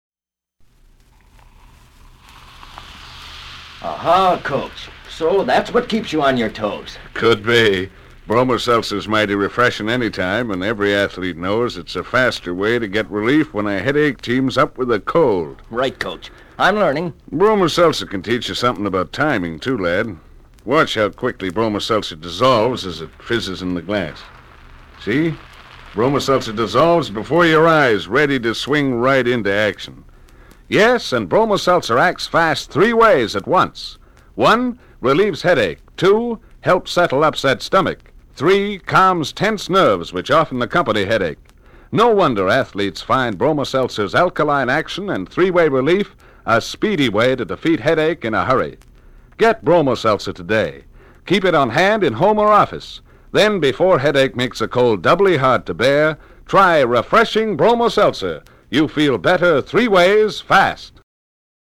NOW...for the vintage stuff!!!! this material is taken from my private collection of 1940's 16 inch radio transcriptions! these records were what those golden age of radio shows were usually recorded on, as this was before tape!!!! these were HUGE!!! 4 inches wider than a LP, but could only hold about 15 mins a side!!!
This spot is dated received january 1946, so I think it was cut in late 1945.